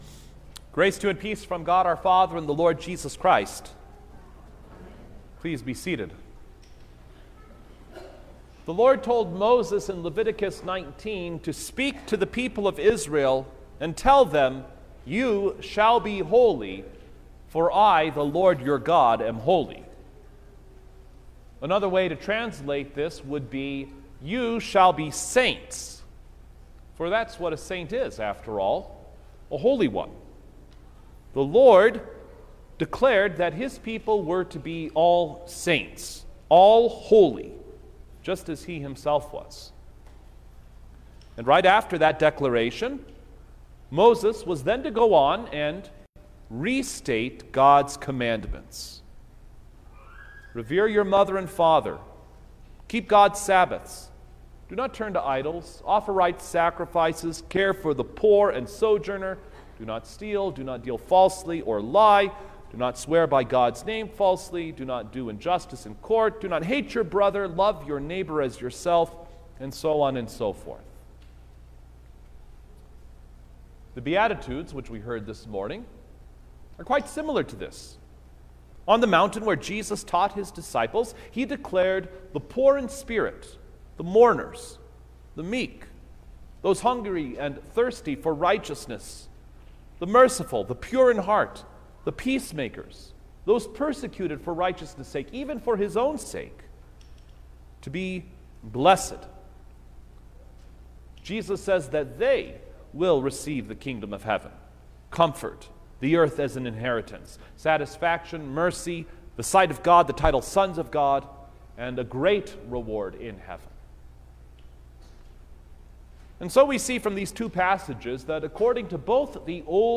November-6_2022_All-Saints-Day_Sermon-Stereo.mp3